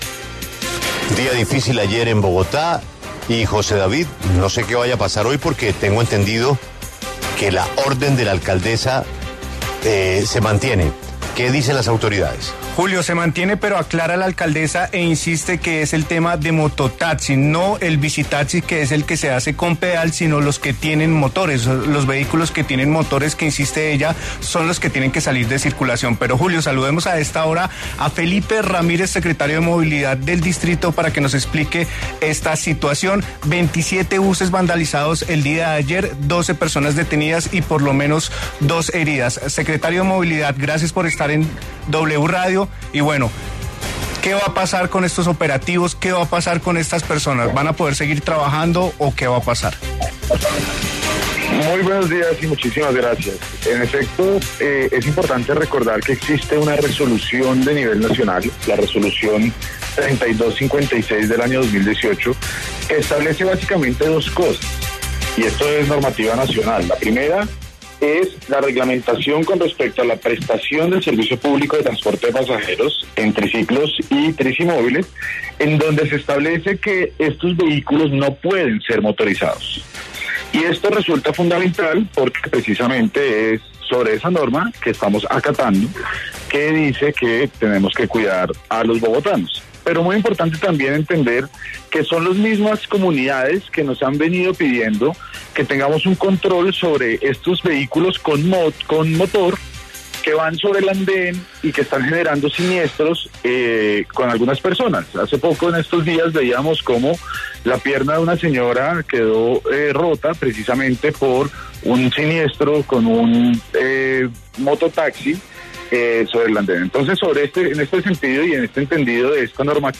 En charla con La W, Felipe Ramírez, secretario de Movilidad de Bogotá, hizo un balance sobre la situación de los bicitaxistas y mototaxistas en la capital.